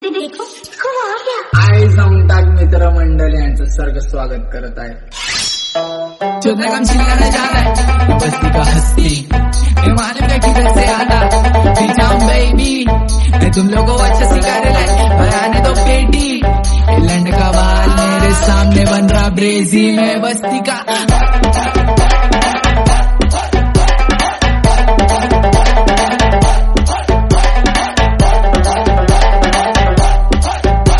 Hindi Ringtones, Ringtones